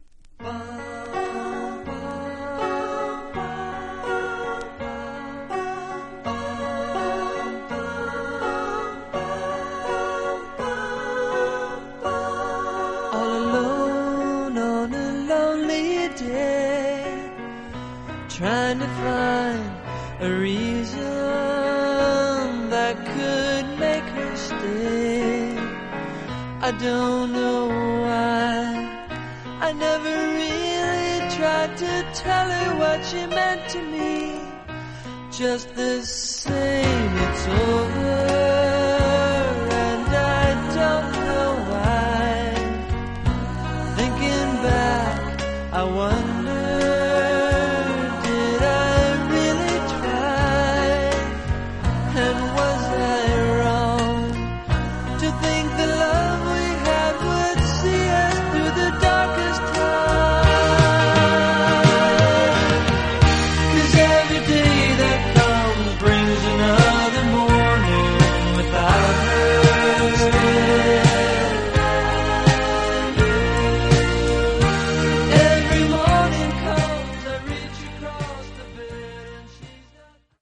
実際のレコードからのサンプル↓ 試聴はこちら： サンプル≪mp3≫ 出价者 信用 价格 时间